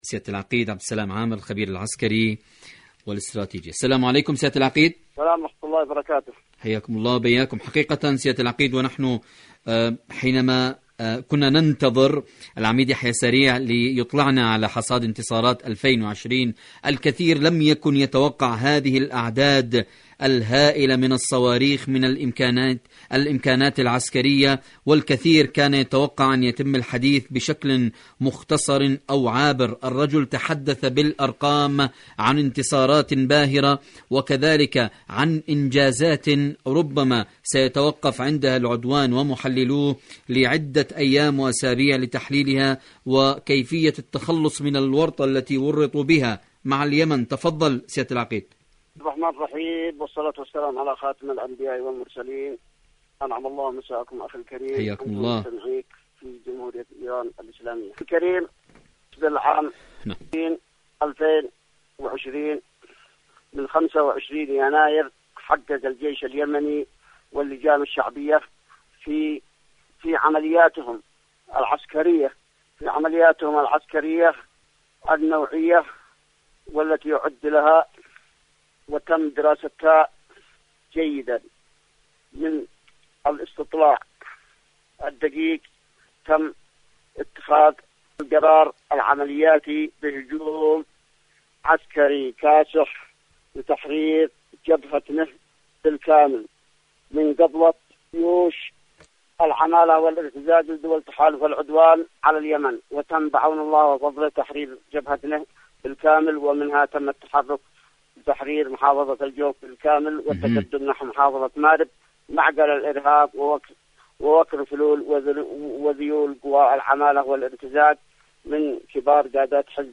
مقابلات إذاعية